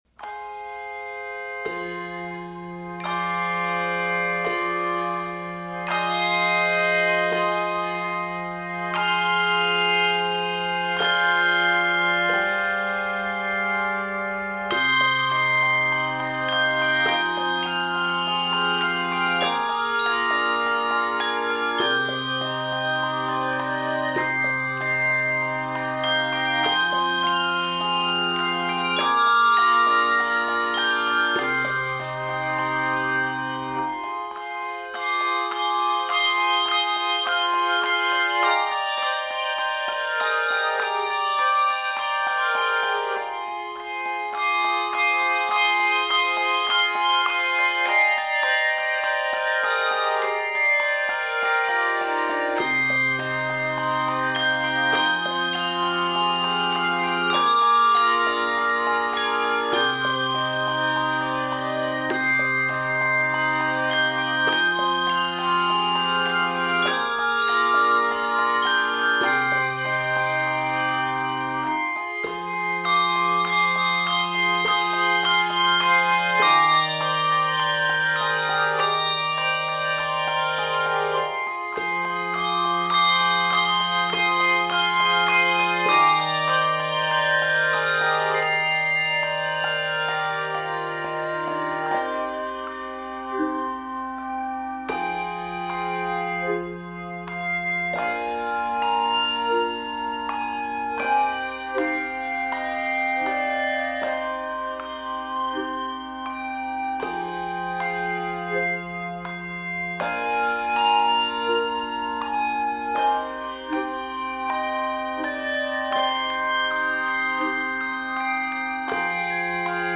Key of c minor.